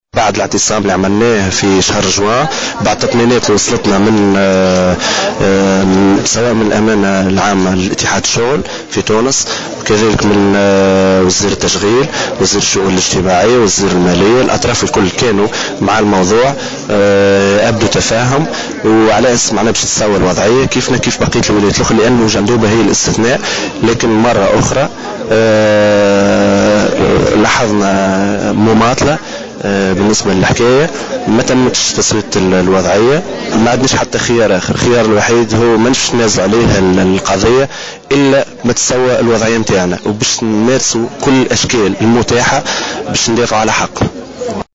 وأكد أحد المشاركين في هذه الوقفة الاحتجاجية ل"جوهرة أف أم" أن هذا التحرّك يأتي على خلفية ما اعتبره مماطلة في تسوية وضعياتهم،مشيرا إلى أنهم سيواصلون تحرّكاتهم الاحتجاجية إلى حين تلبية مطالبهم.